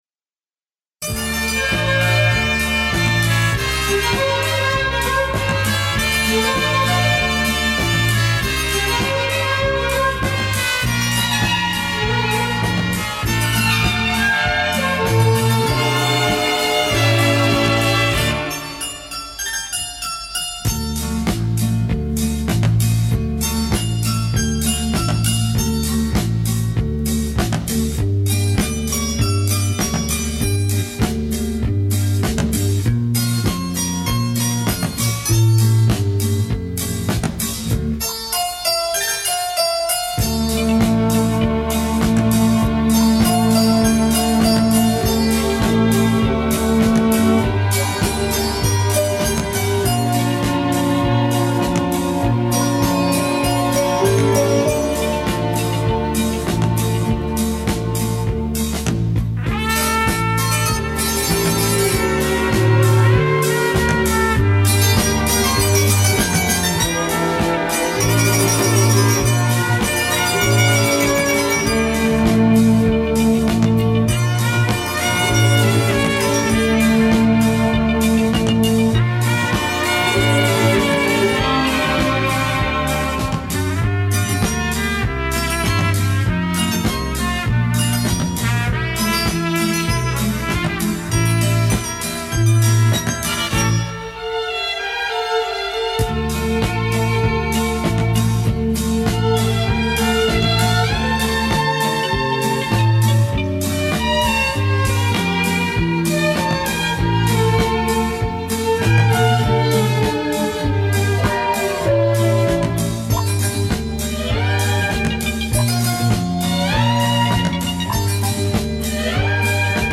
хотя бы псевдостерео.